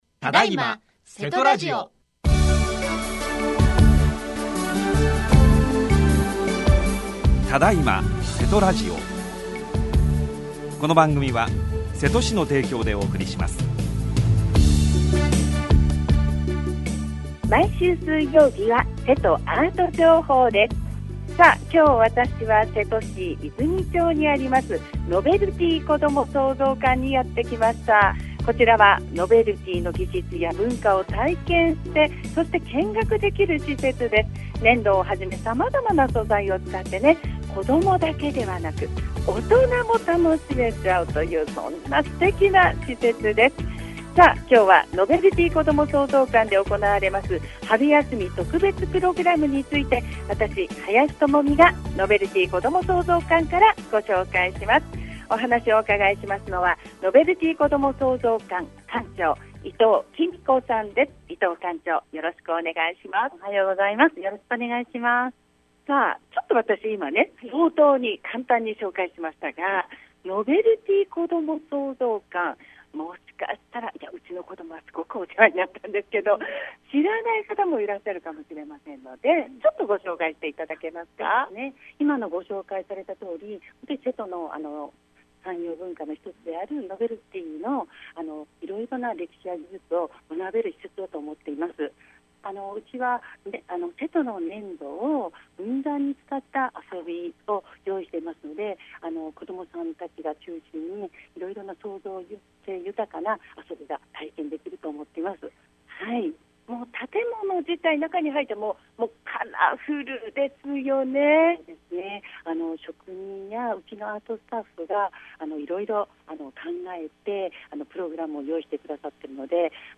今回は、現場レポートでお送りしました。